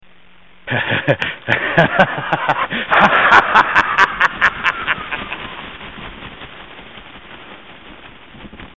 Dracula's laugh
Category: Radio   Right: Personal